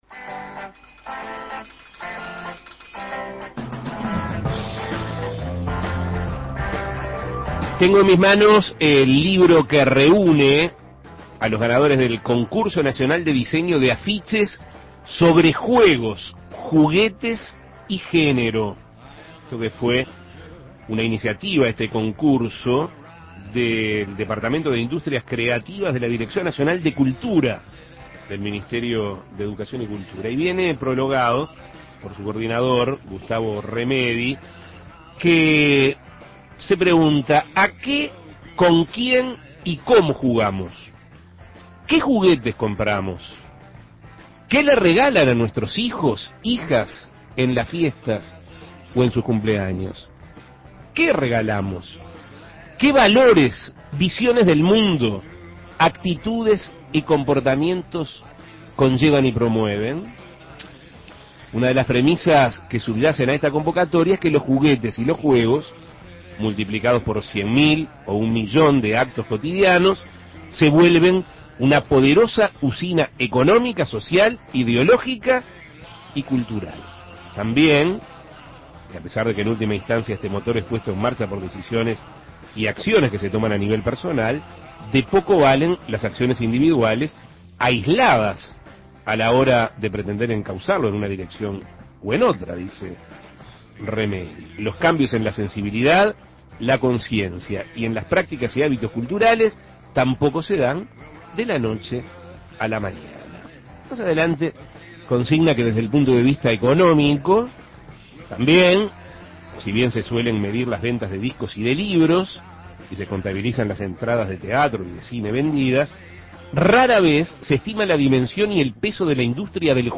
Se trata de una escultura ambulante que transporta y regala libros, recibiendo donaciones de cualquier parte y llevándolas a los lugares más recónditos de la Argentina y del continente. Escuche la entrevista.